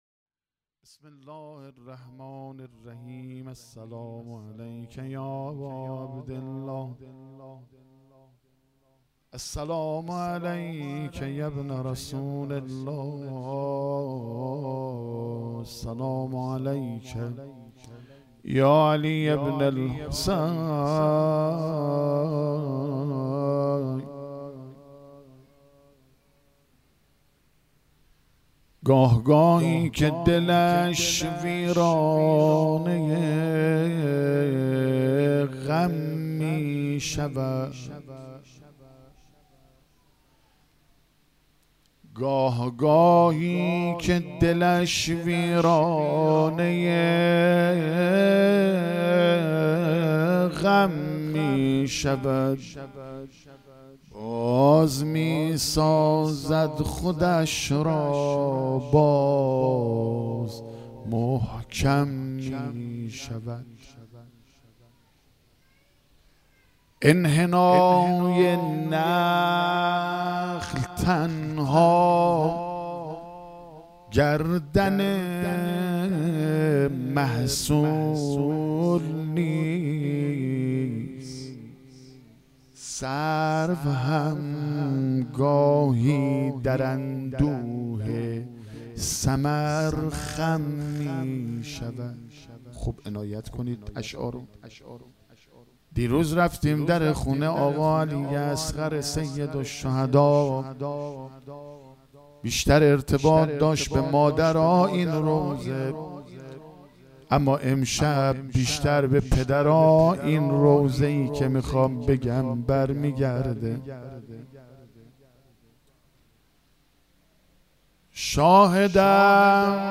روضه | گاه گاهی که دلش ویرانه غم میشود مداح
محرم1442_شب هشتم